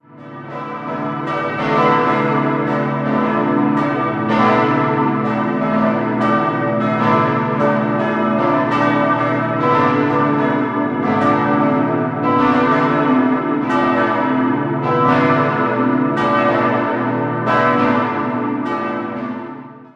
Den seitlichen Glockenturm, der den ursprünglichen Dachreiter ersetzte, erhielt das Gotteshaus erst im Jahr 1915. 4-stimmiges Geläut: a°-h°-d'-e' Die beiden größeren Glocken wurden 2002 von der Gießerei Mark in Brockscheid (Eifel) gegossen, die kleineren stammen aus dem Jahr 1990 von Mabilon (Saarburg).